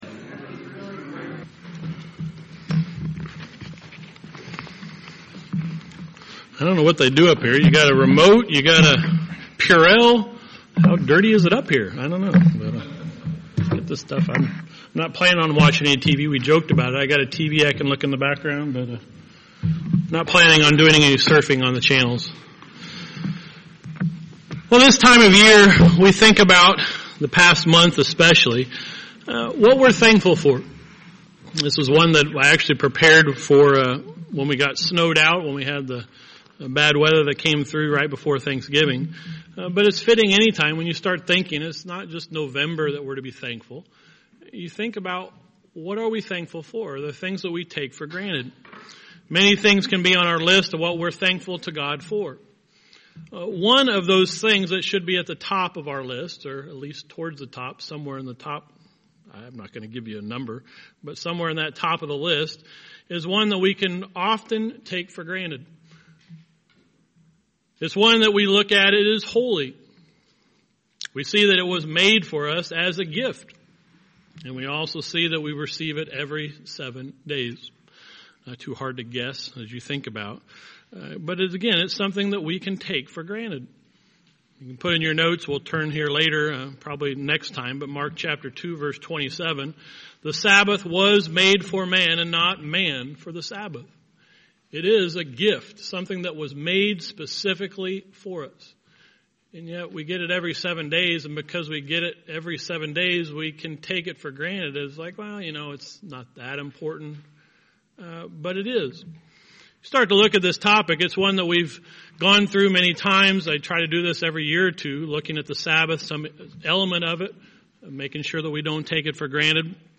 The beginning of a series of sermons on the Sabbath. This sermon is part 1 on the Sabbath in the Old Testament.